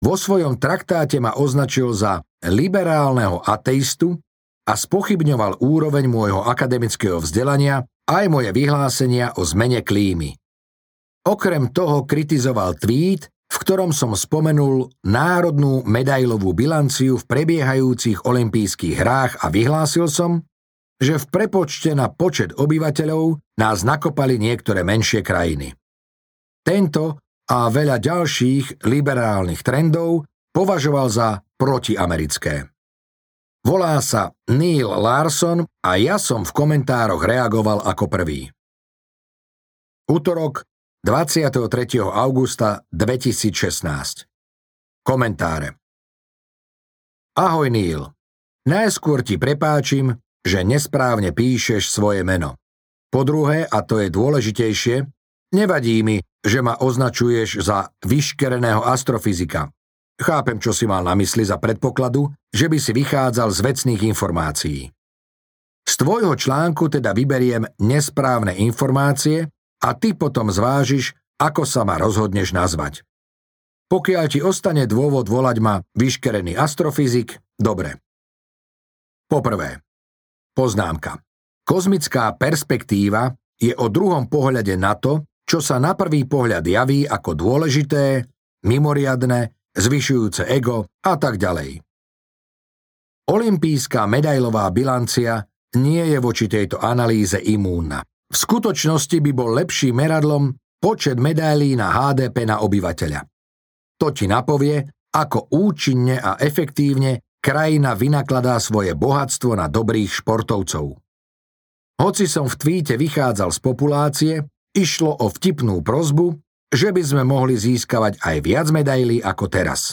Listy od astrofyzika audiokniha
Ukázka z knihy